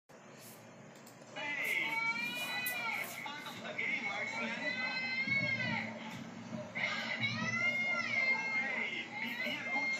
tsm all characters crying sound effects free download